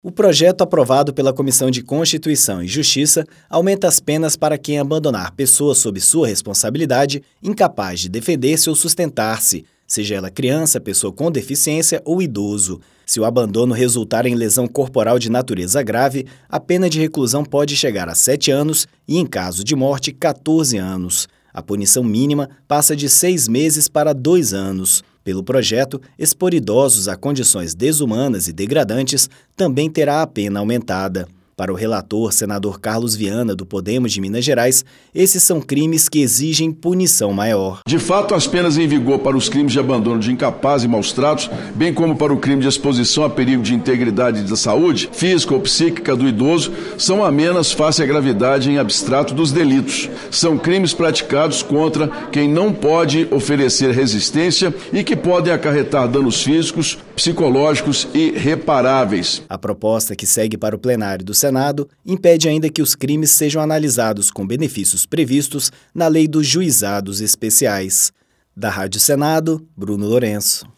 A Comissão de Constituição e Justiça aprovou penas maiores para os crimes de abandono de incapaz e de maus-tratos contra idosos. O relator, Carlos Viana (Podemos-MG), explicou ainda que o projeto impede que os crimes sejam analisados com benefícios previstos na Lei dos Juizados Especiais.